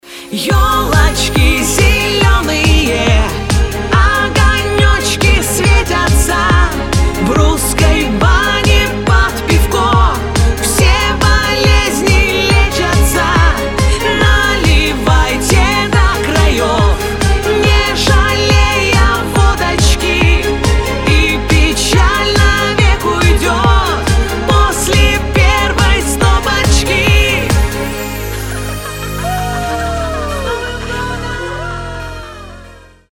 позитивные
праздничные